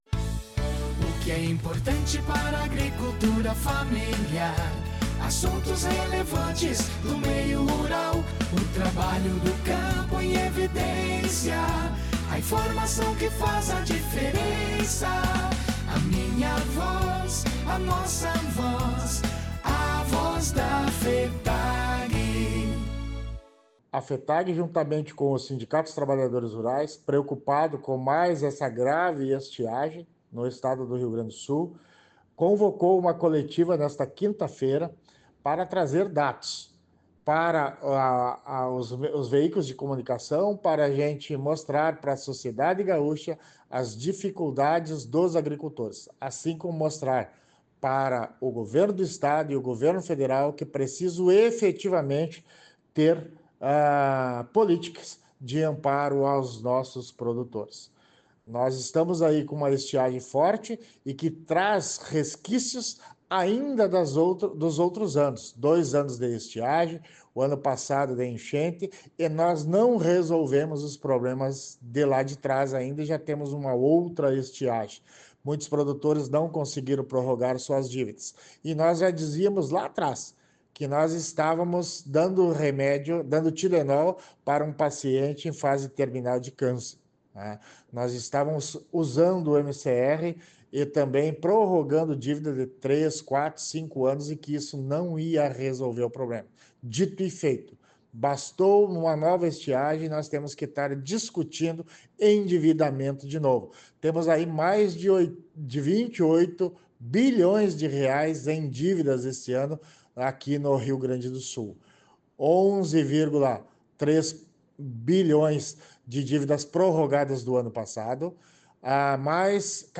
A Voz da Fetag-RS – Coletiva de imprensa sobre estiagem, endividamento e Proagro